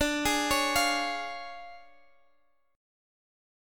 DmM7b5 Chord